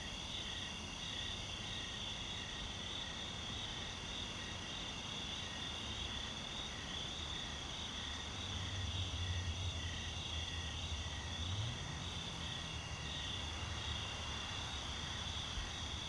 tenkoku_nightambient.wav